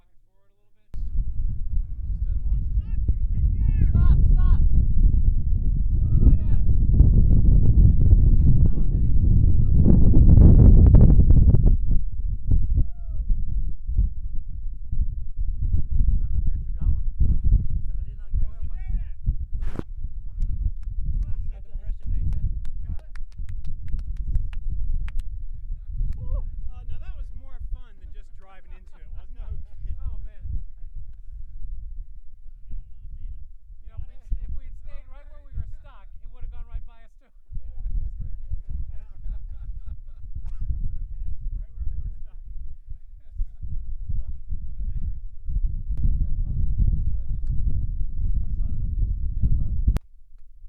A University of Arizona at Tucson truck was outfitted with a specialized array of instrumentation to study the atmospheric, electrical, and acoustic properties of dust devils. The tests took place in Eloy, Arizona June 5-7, 2001 and involved scientists from UC Berkeley, the Jet Propulsion Laboratory, the NASA Goddard Space Flight Center, and the University of Arizona Tucson.
truck_stuck_devil.wav